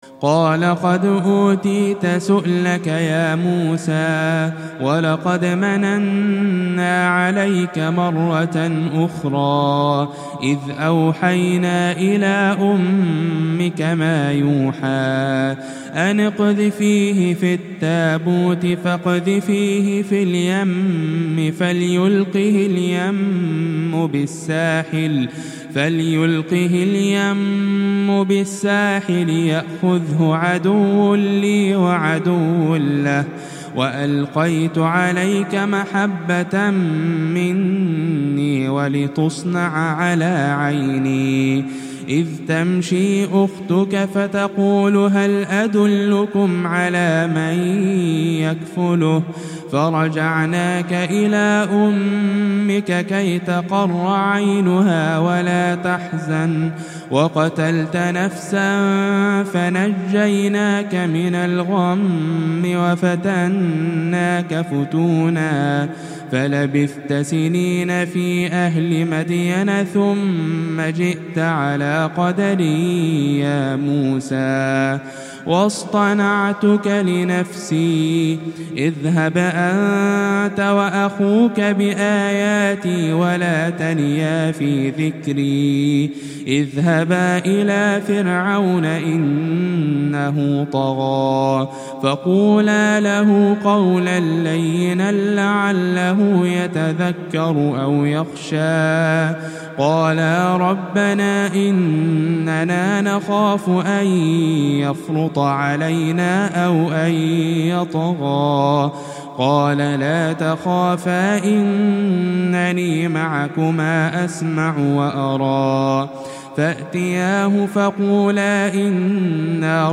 تلاوة هادئة
تلاوة طيبة هادئة من سورة طه